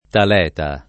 Taleta [ tal $ ta ]